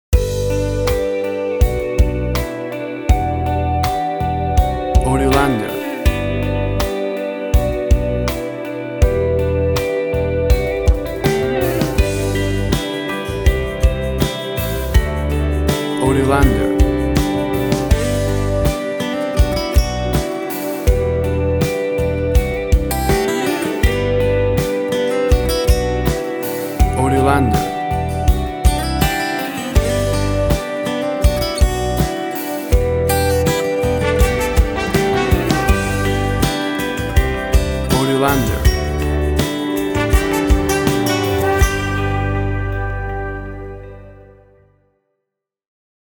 WAV Sample Rate: 24-Bit stereo, 44.1 kHz
Tempo (BPM): 81